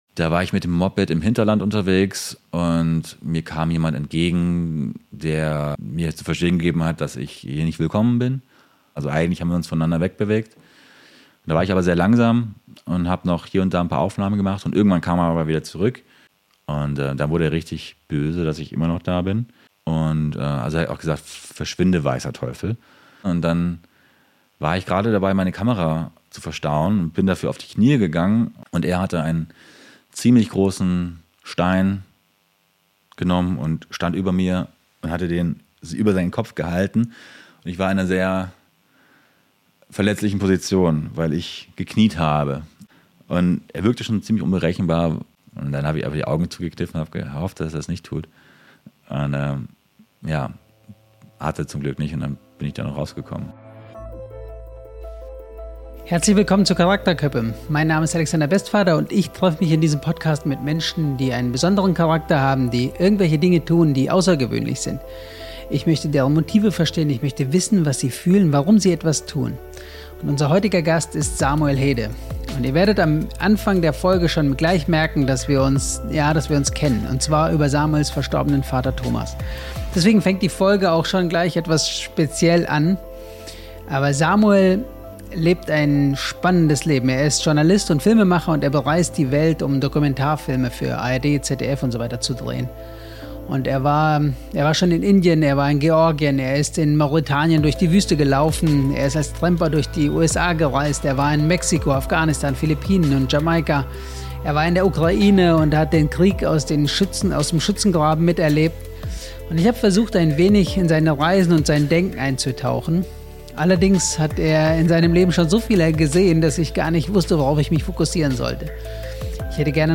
Ein Gespräch mit einem, der die Welt gesehen hat – und trotzdem auf der Suche bleibt.